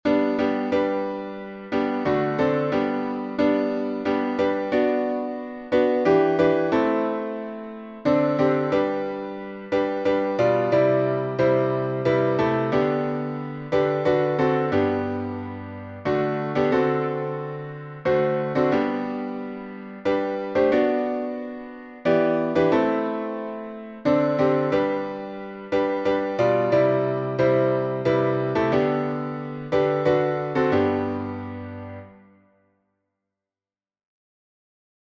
Close to Thee — alternate harmonies.